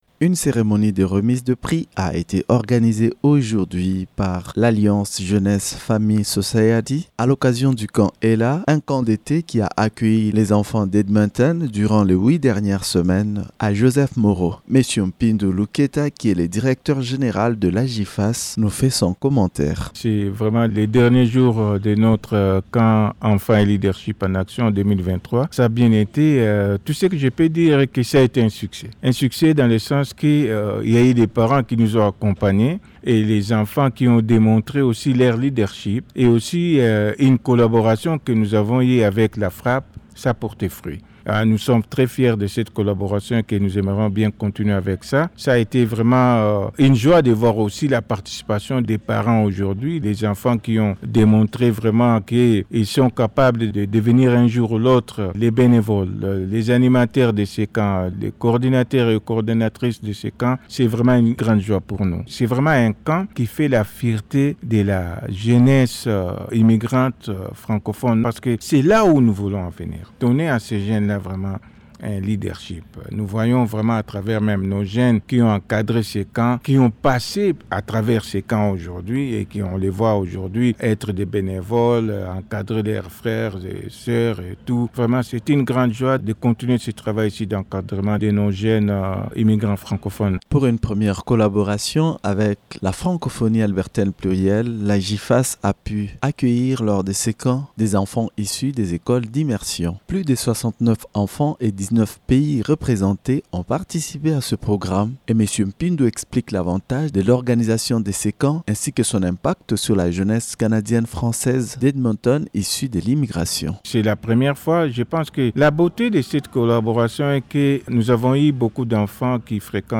Entrevue-AJFAS-Camp-ELA.mp3